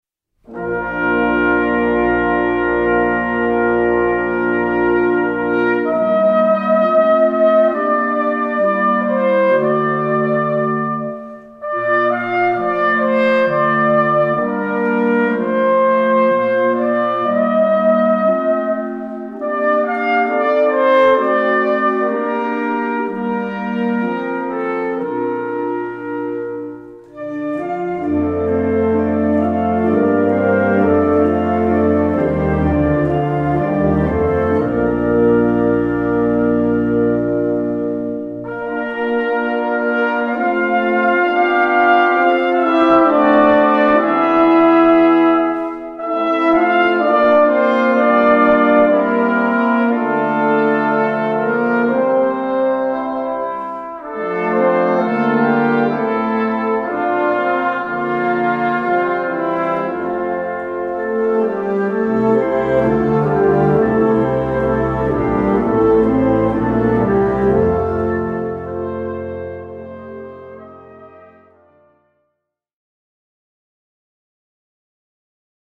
Répertoire pour Harmonie/fanfare - Musique classique